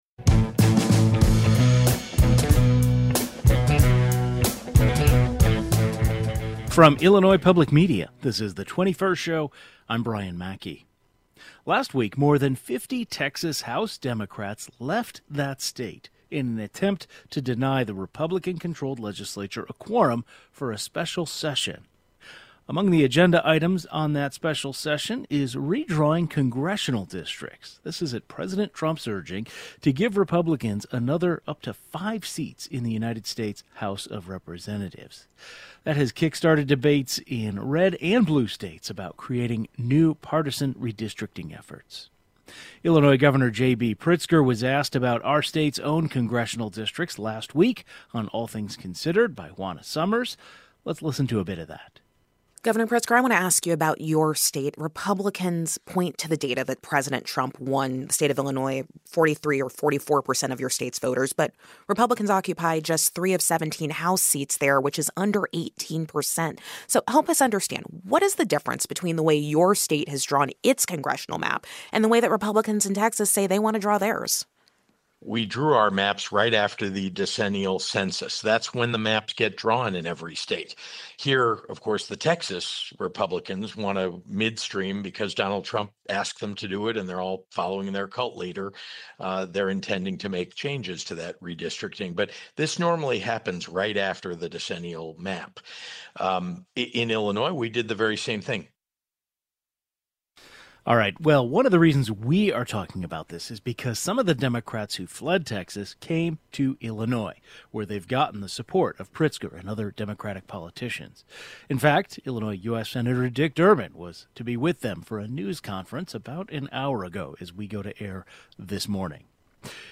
Some of the Democrats who left Texas came to Illinois, where they've gotten the support of Governor Pritzker and other Democratic politicians. Two journalists following this story join today's program.